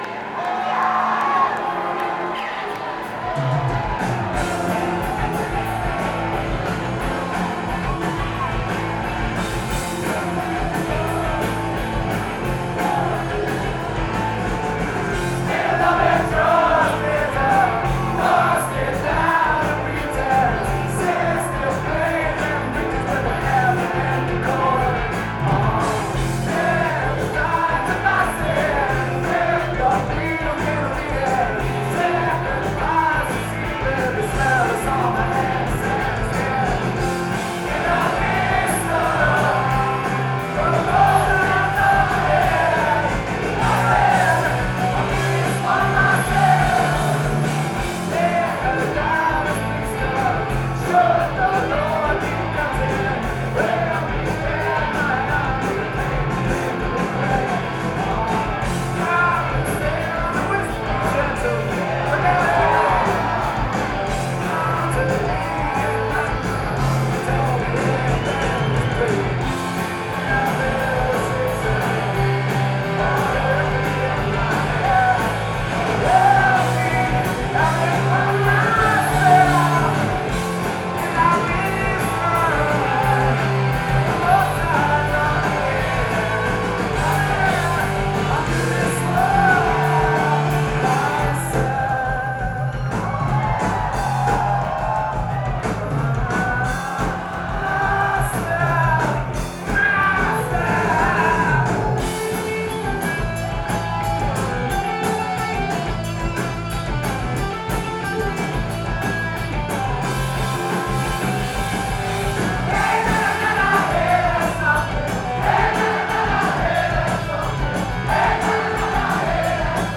Genre: Grunge